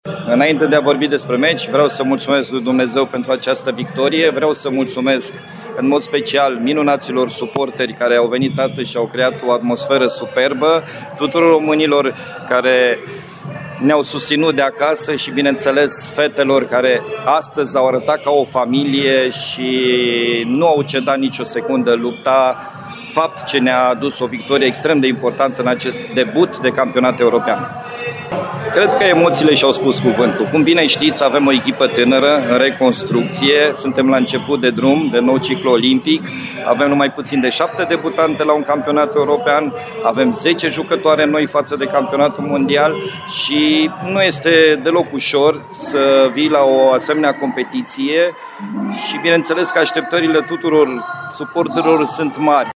„Le mulțumesc lui Dumnezeu și suporterilor pentru această victorie”, a spus selecționerul Pera:
Pera-echipa-unita.mp3